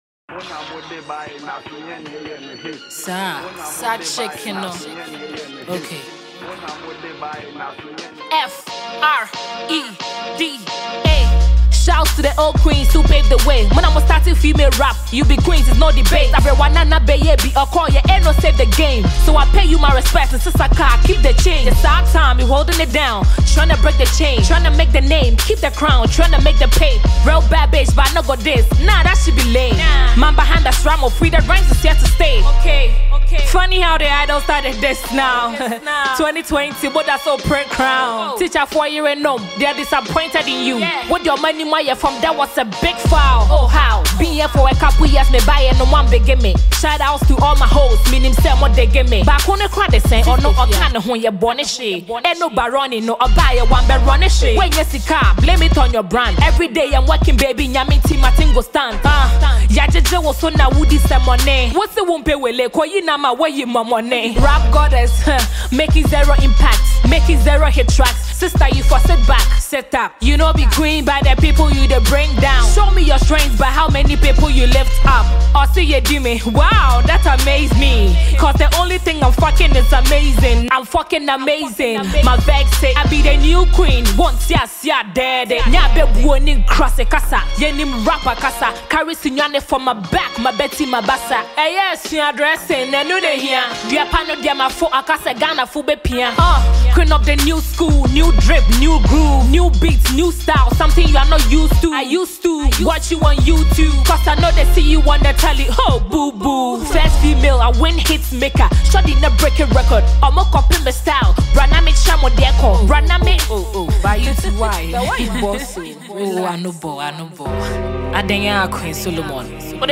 Ghanaian sensational female rap act
a solid diss reply.